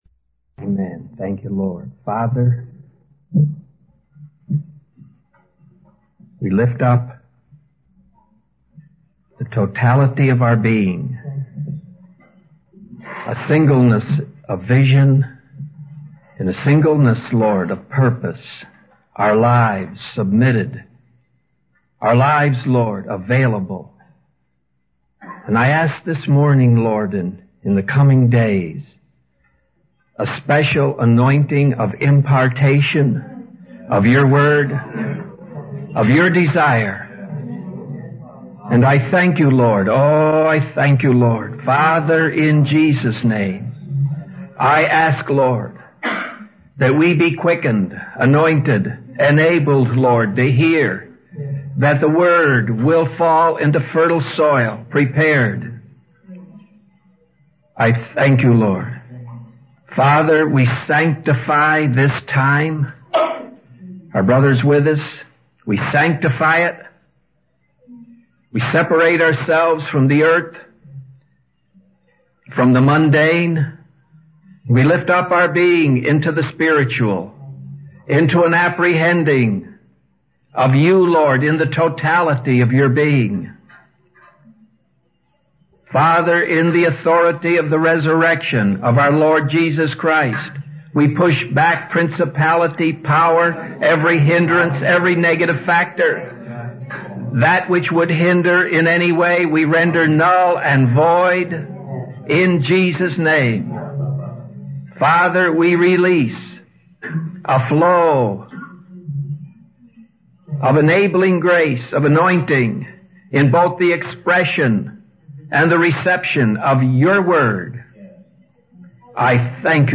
In this sermon, the speaker emphasizes that the timing of God's work is not determined by human understanding or limitations. He assures the audience that God will fulfill His promises and nothing can hinder His plans. The speaker also highlights the importance of obedience and willingness in serving God, rather than relying on human wisdom or eloquent speech.